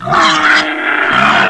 pain3.ogg